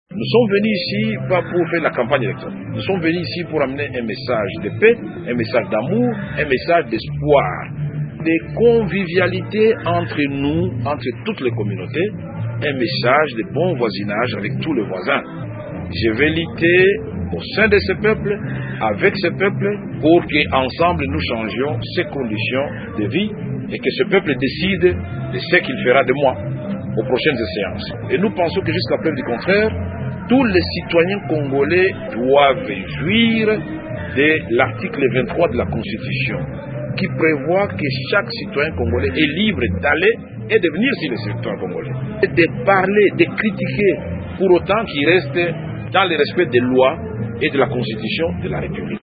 Avant de quitter Bukavu, il a expliqué lui-même ce dimanche au cours d’une conférence de presse le sens et l’objectif de sa tournée: